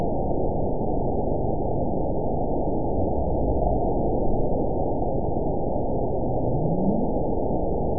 event 917328 date 03/27/23 time 22:46:37 GMT (2 years, 1 month ago) score 9.14 location TSS-AB03 detected by nrw target species NRW annotations +NRW Spectrogram: Frequency (kHz) vs. Time (s) audio not available .wav